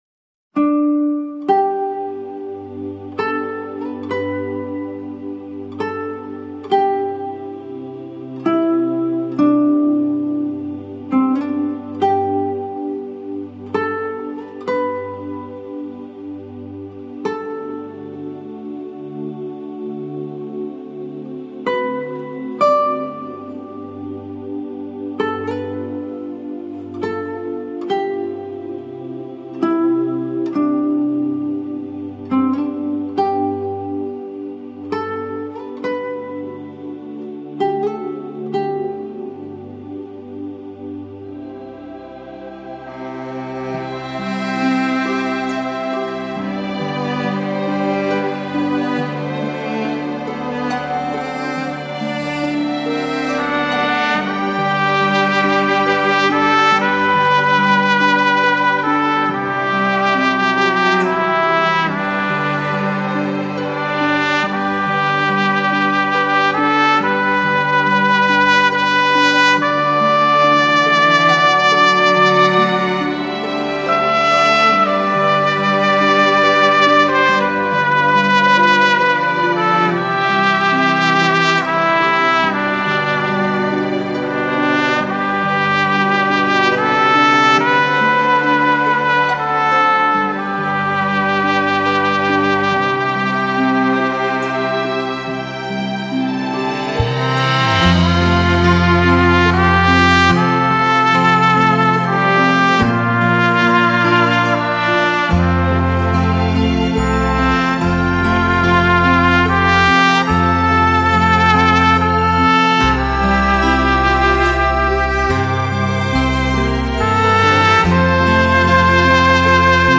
整张专辑采用柔美、抒情的音乐风格，温暖、如歌的小号旋律与精致、
优雅的伴奏音乐交相辉映，重新演绎人们耳熟能详的经典国外名歌、名曲。
钢琴